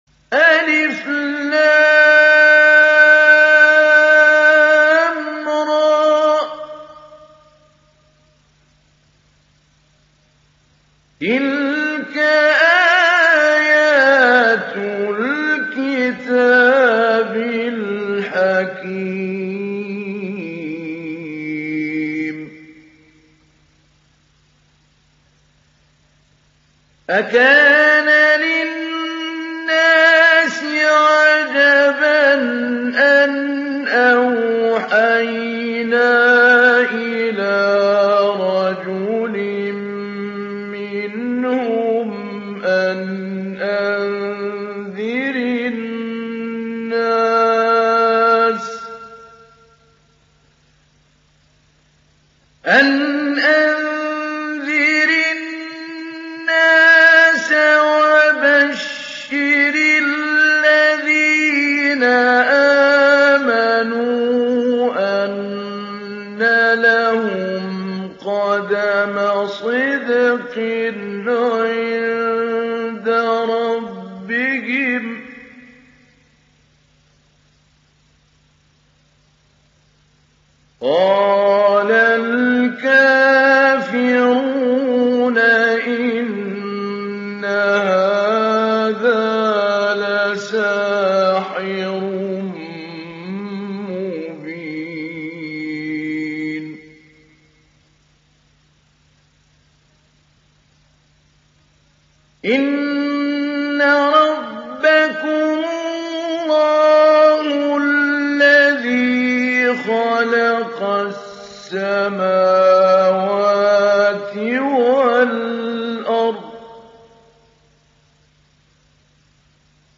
تحميل سورة يونس mp3 بصوت محمود علي البنا مجود برواية حفص عن عاصم, تحميل استماع القرآن الكريم على الجوال mp3 كاملا بروابط مباشرة وسريعة
تحميل سورة يونس محمود علي البنا مجود